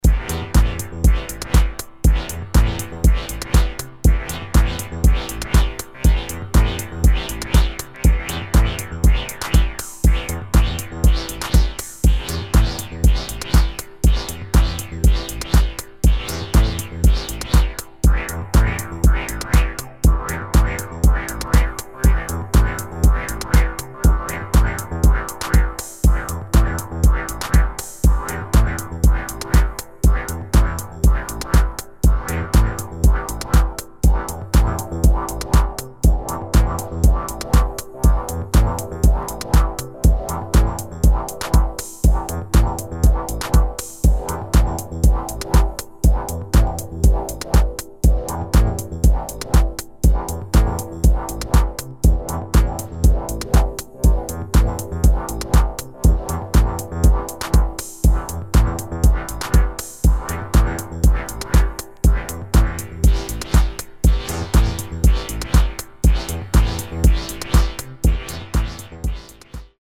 [ TECHNO / MINIMAL / ACID / HOUSE ]